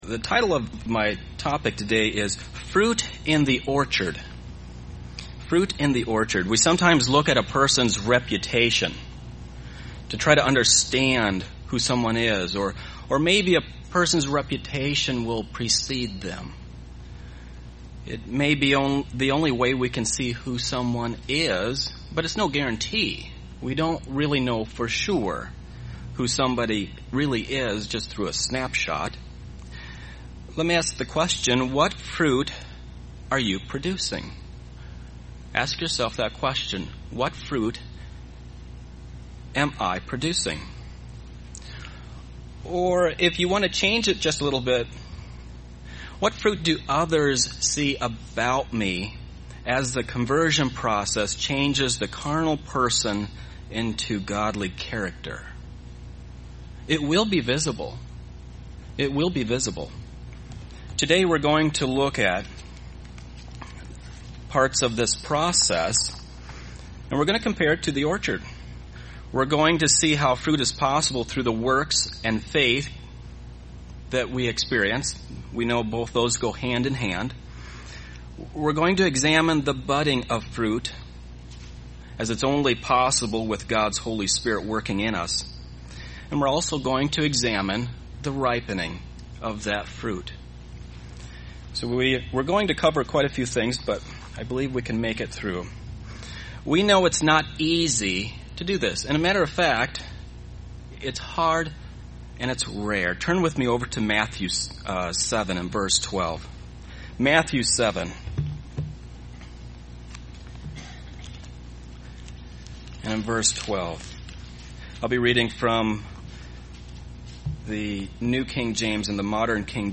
UCG Sermon Studying the bible?
Given in Beloit, WI